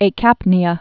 (ā-kăpnē-ə)